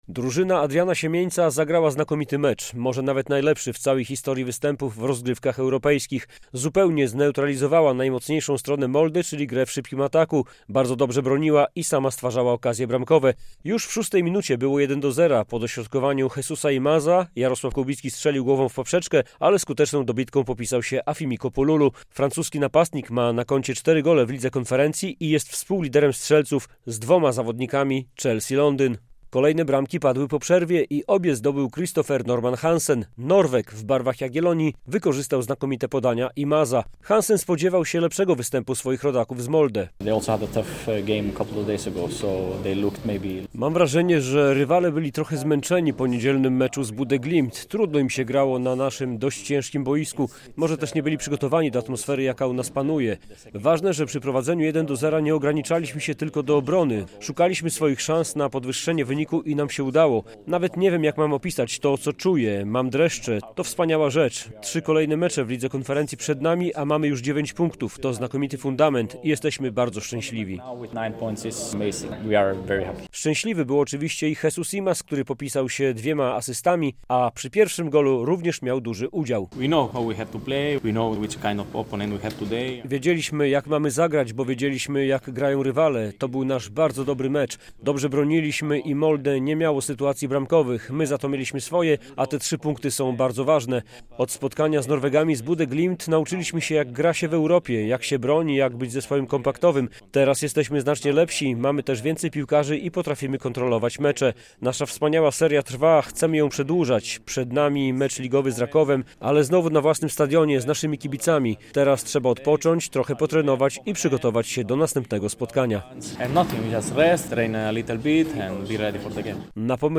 Jagiellonia Białystok pokonała norweski klub Molde - relacja